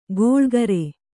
♪ goḷgare